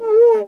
LATIN LICK 2.wav